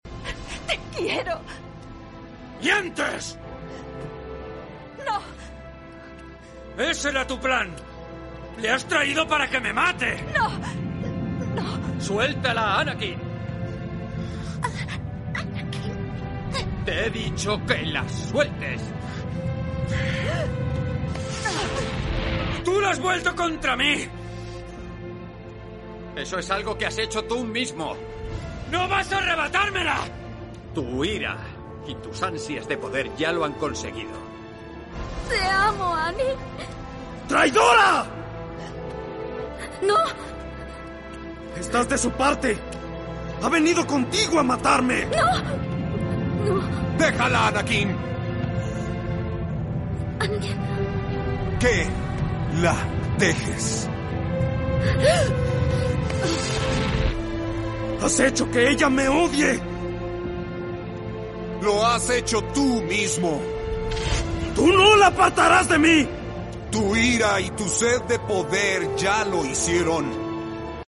Anakin y Obi-Wan | Comparación de Doblaje